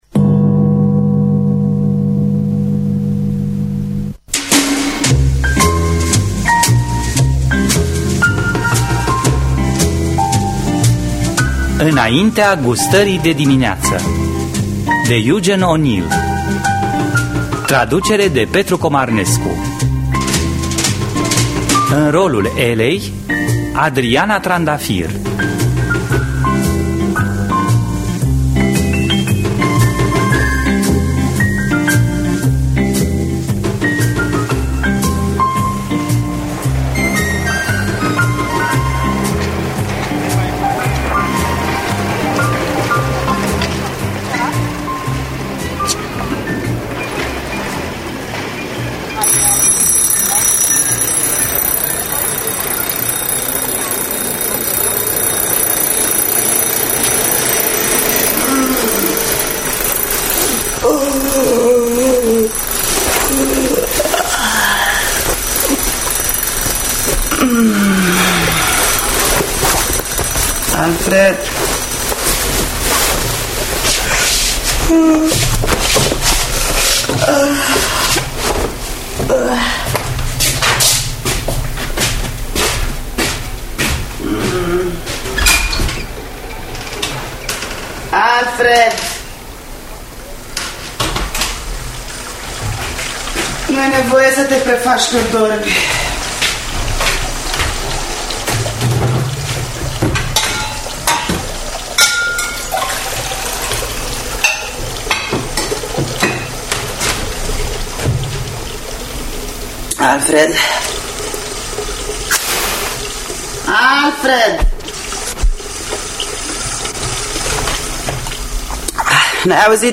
Înaintea gustării de dimineață de Eugene O’Neill – Teatru Radiofonic Online
Interpretare: Adriana Trandafir.